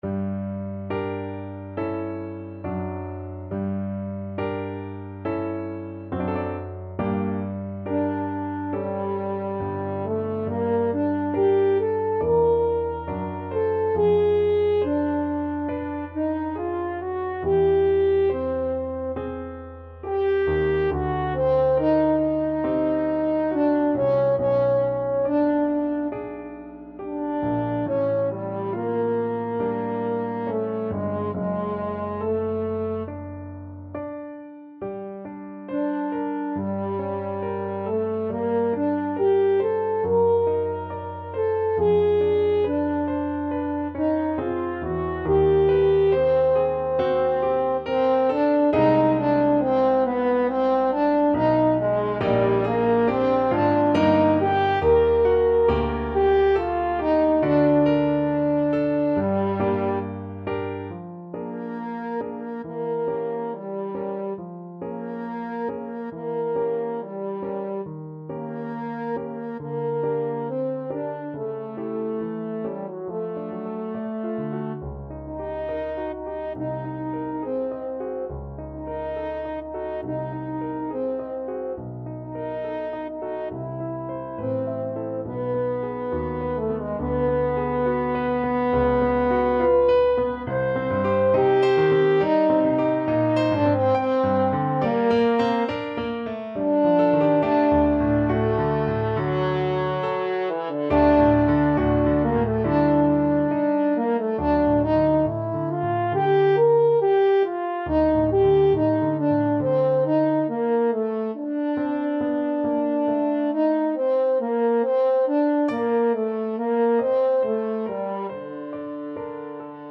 French Horn version
4/4 (View more 4/4 Music)
~ = 69 Andante con duolo
Classical (View more Classical French Horn Music)